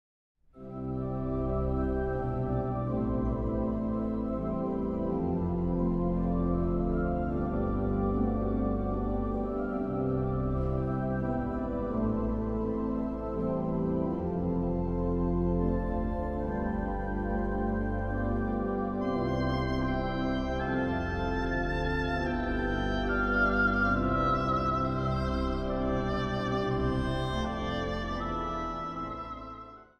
orgel
hobo
koorsolisten.
Zang | Mannenkoor